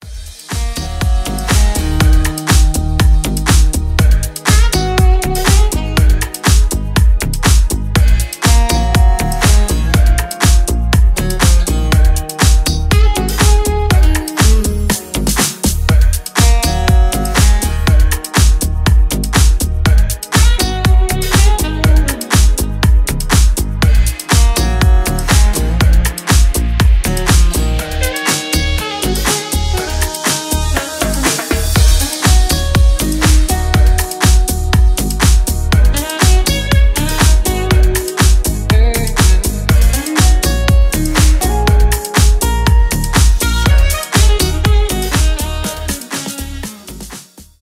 инструментальные
deep house
романтические , саксофон , без слов